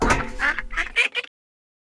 duck.wav